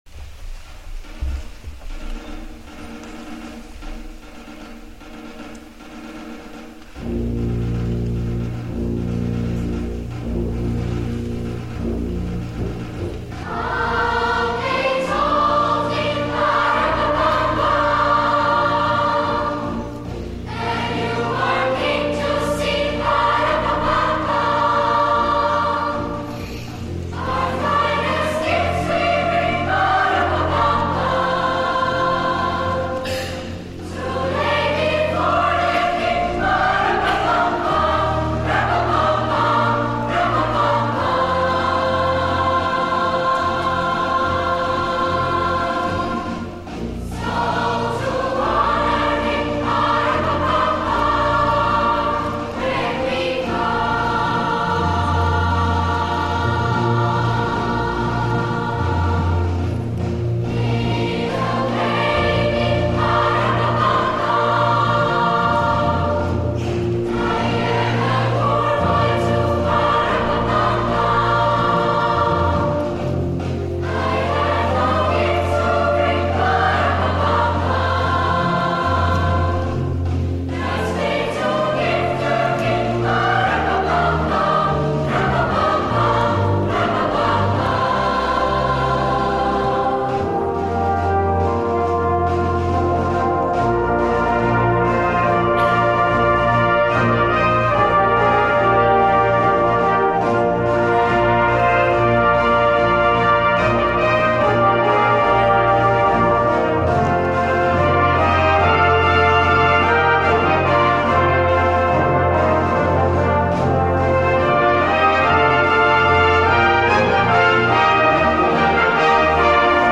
Weihnachtskonzert 2013
Die Juventus Singers und die Brass Band der Musikschule Michelsamt stimmten das Publikum auf die Weihnachtstage ein.
Ort: Pfarrkirche von Rickenbach
Wir entschuldigen uns für die bescheidene Tonqualität, die Aufnahmen wurden mit einem einfachen Raum-Mikrofon erstellt.